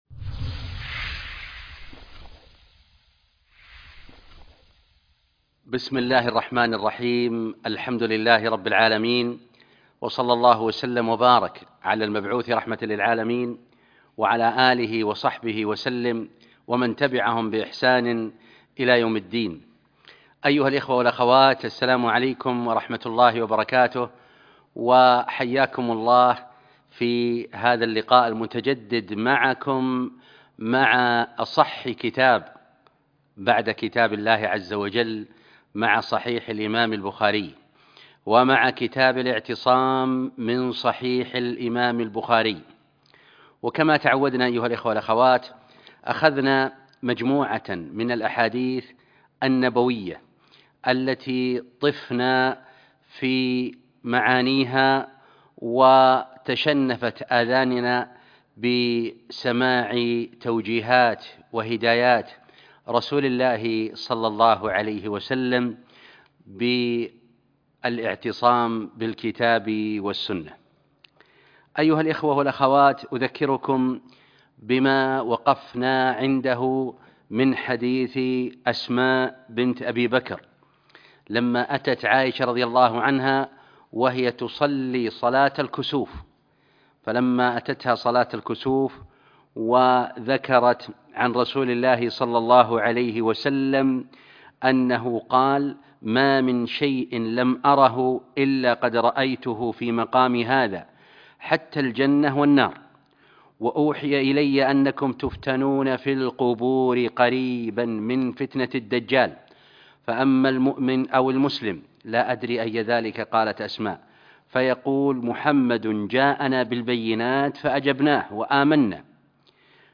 الدرس( 10) شرح كتاب الاعتصام بالكتاب والسنة من صحيح البخاري - قسم المنوعات